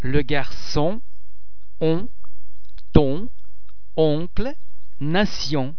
The French [on] nasal vowel sound is made up of the [o ] vowel base which is subsequently nasalised by the air being passed through the mouth and the nostrils at the same time.
·on
on_legarcon.mp3